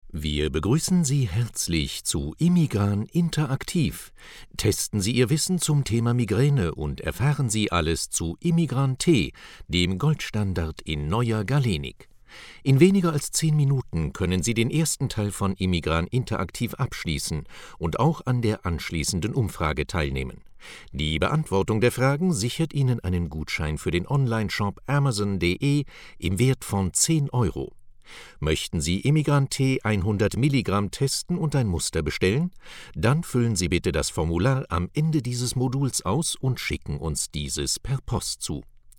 Sprecher deutsch.
Sprechprobe: Industrie (Muttersprache):
german voice over artist